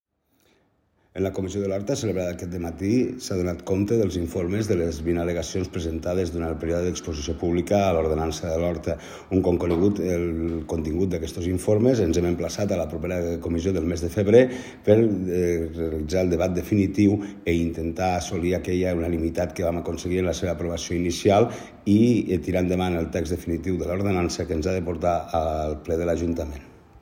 tall-de-veu-del-regidor-david-mele-sobre-la-comissio-territorial-de-lhorta